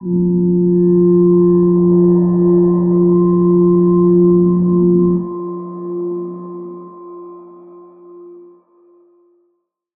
G_Crystal-F4-mf.wav